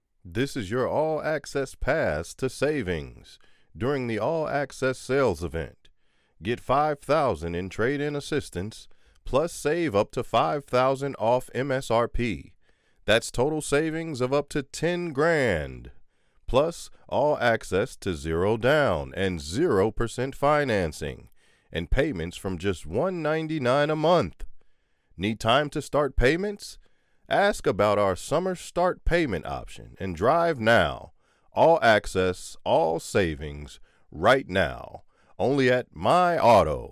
Authentic, confident, honest, treating the audience like family, with a strong, yet compassionate voice.
Audition for All Access Sales Event
Audition for All Access Sales Event.mp3